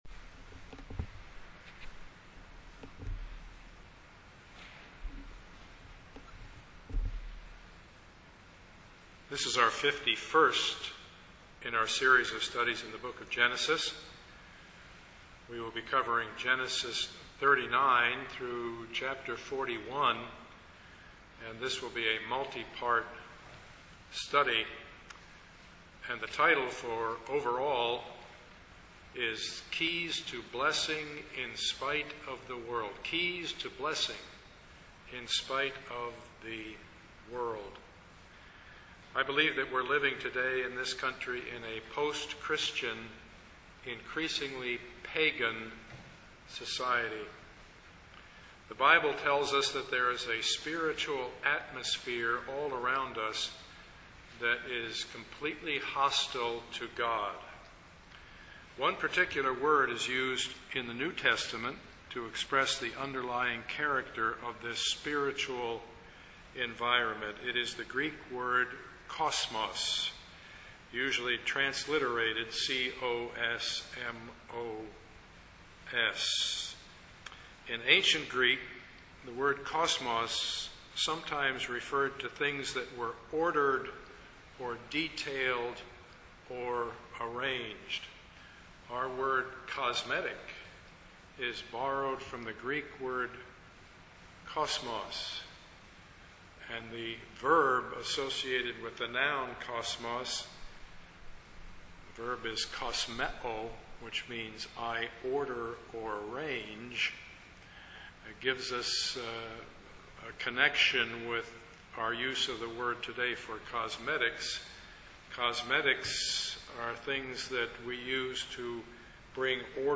Passage: Genesis 39:1-41:57 Service Type: Sunday morning